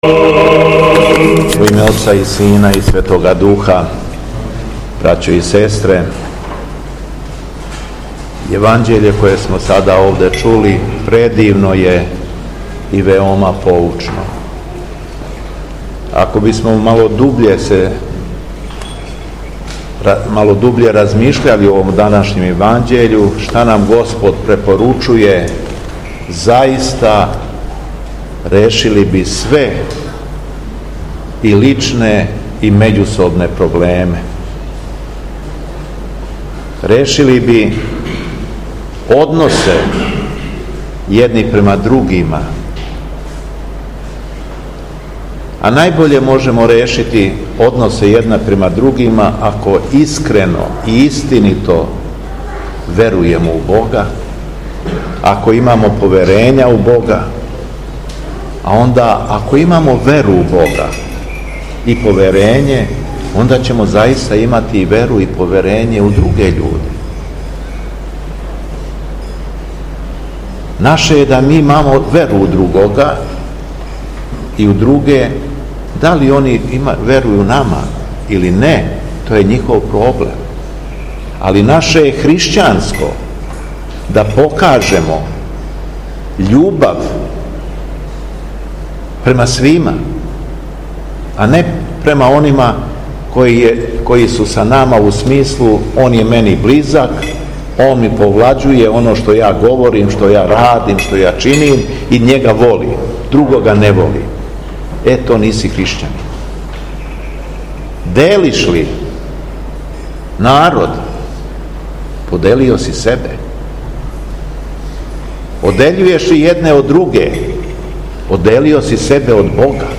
АРХИЈЕРЕЈСКА БЕСЕДА – МИТРОПОЛИТ ШУМАДИЈСКИ Г. ЈОВАН: Имајмо вере у друге и не бринимо да ли они имају вере у нас